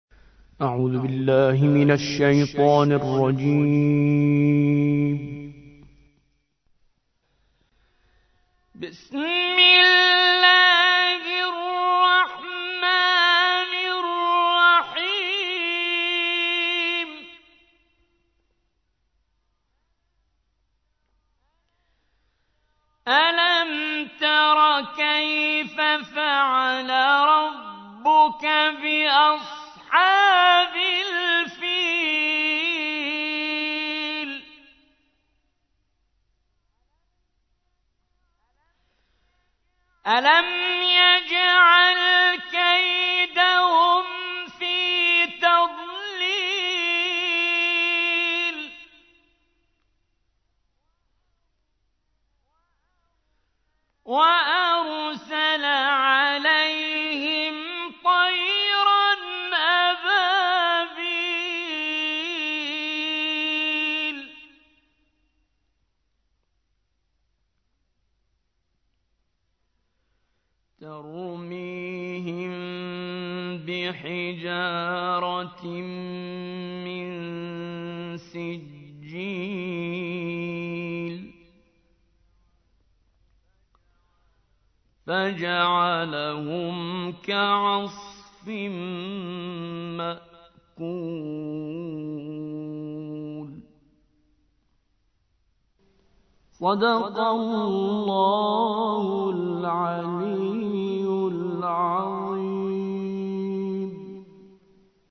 105. سورة الفيل / القارئ